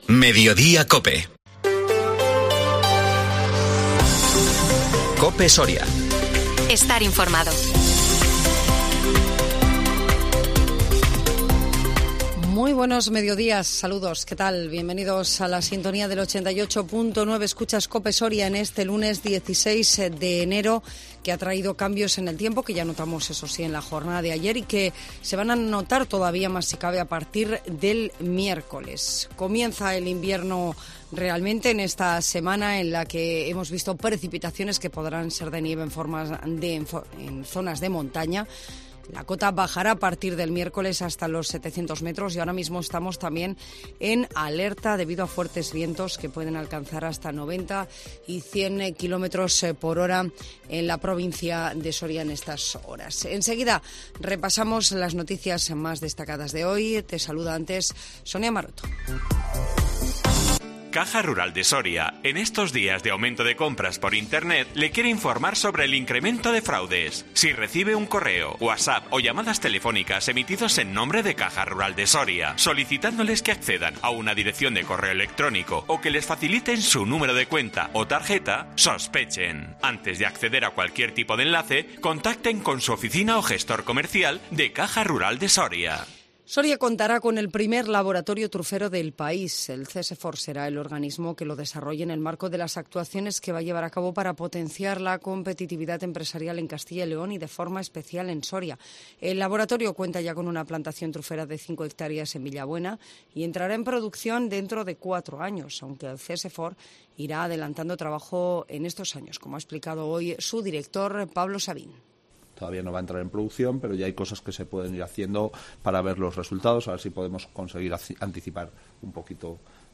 INFORMATIVO MEDIODÍA COPE SORIA 16 ENERO 2023